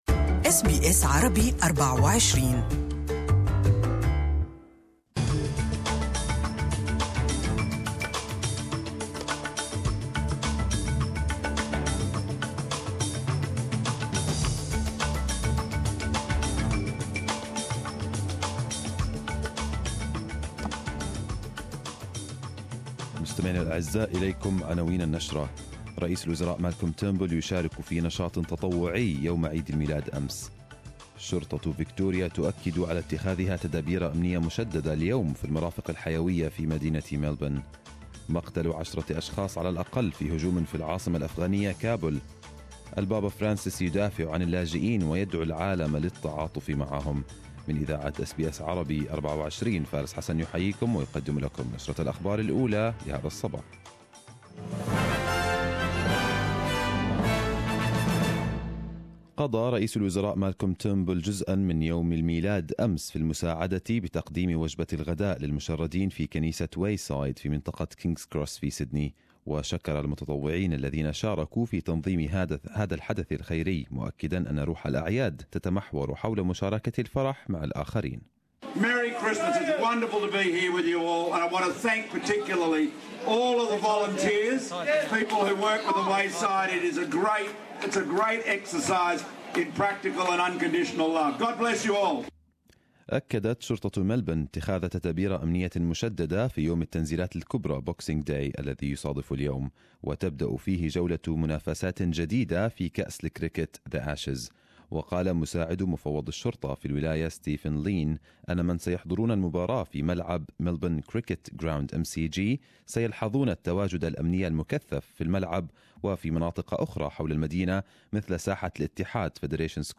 Arabic News Bulletin 26/12/2017